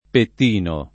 Pettino [ pett & no ]